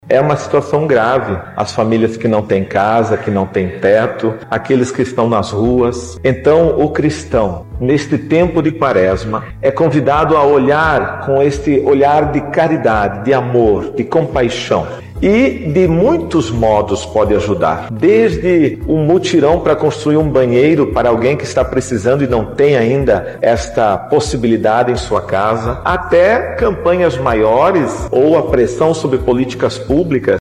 Sonora-2-Dom-Ricardo-Hoepers.mp3